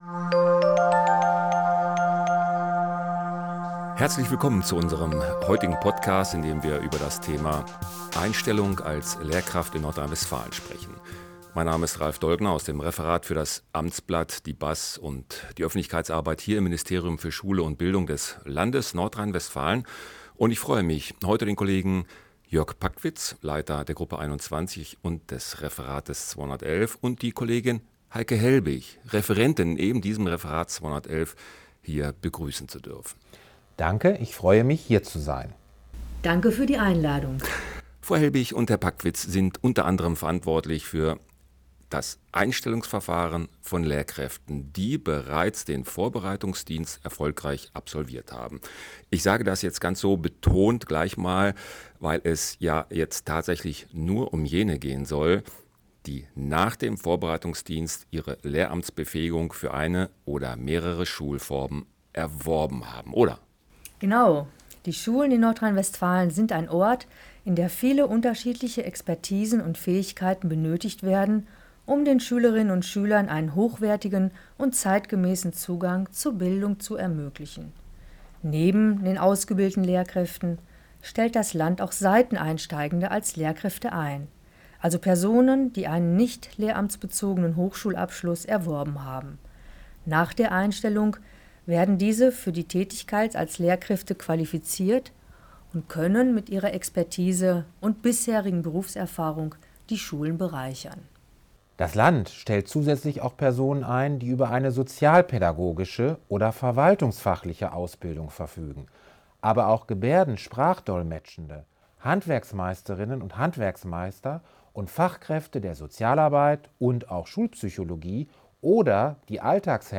Aufnahme vom 17. Dezember 2024 im Ministerium für Schule und Bildung des Landes Nordrhein-Westfalen